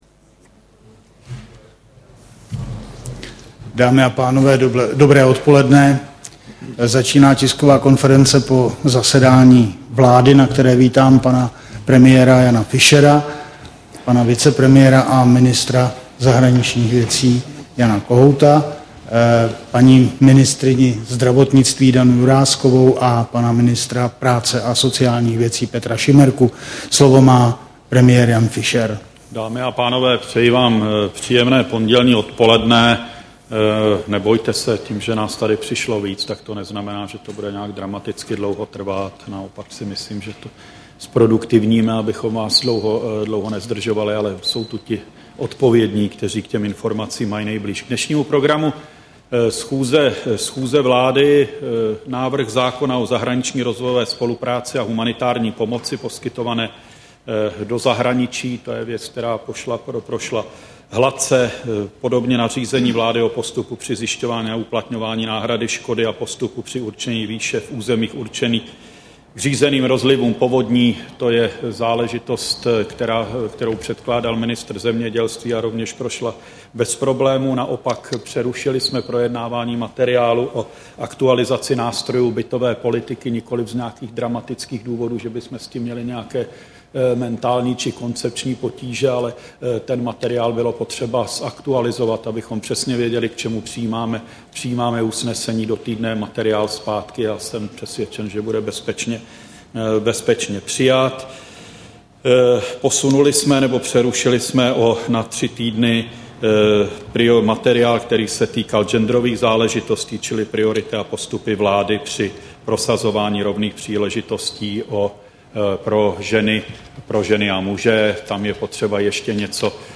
Tisková konference po zasedání vlády, 22. června 2009